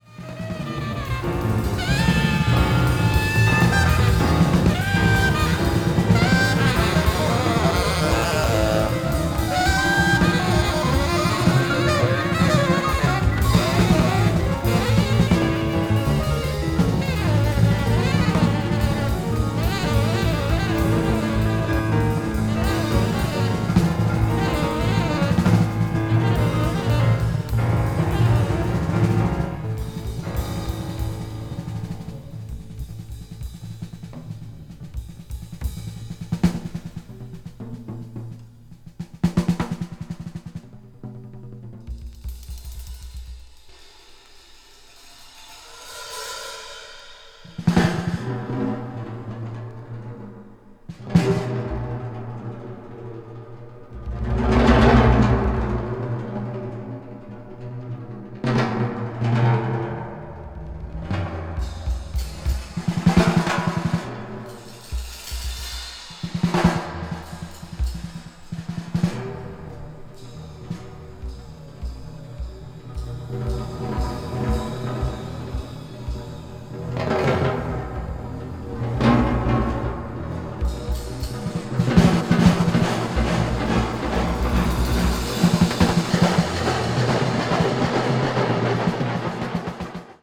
avant-jazz   contemporary jazz   free jazz